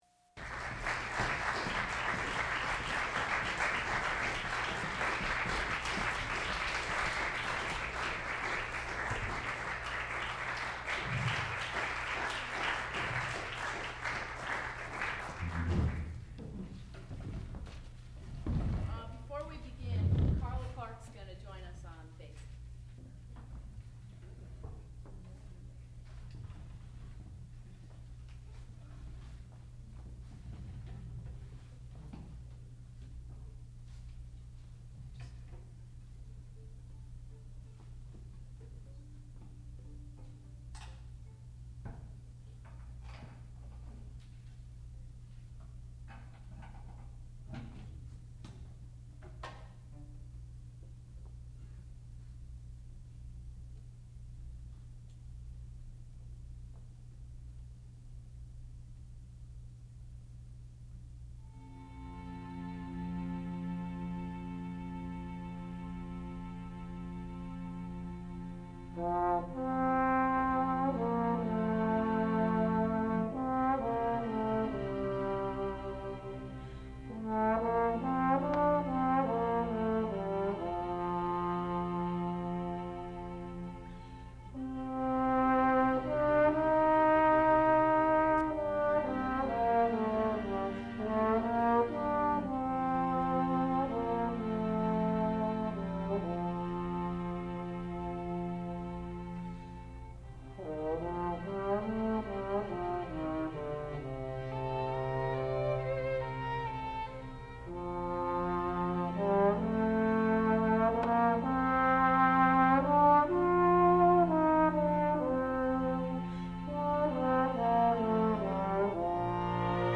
sound recording-musical
classical music
trombone
violoncello
viola
Master's Recital